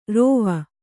♪ rōva